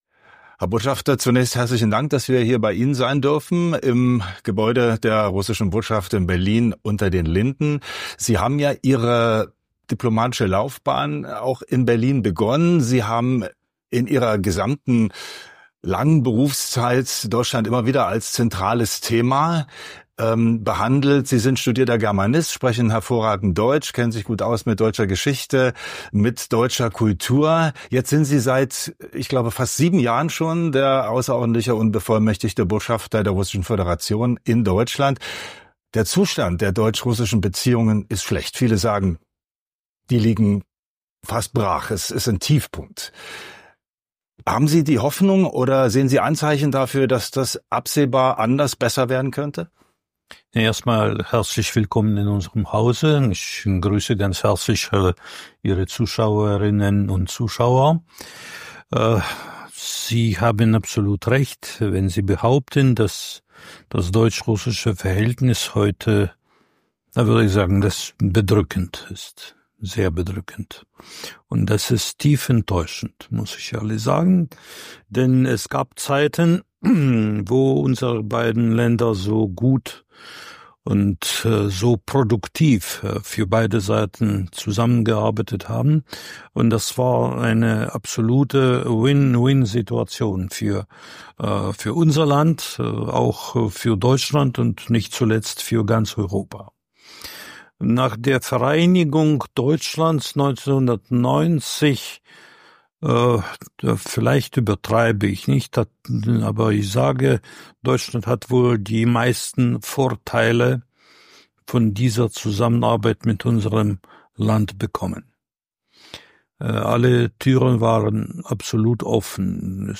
spricht mit dem russischen Botschafter über den Ukraine-Krieg, mögliche Wege zum Frieden und die Haltung Moskaus.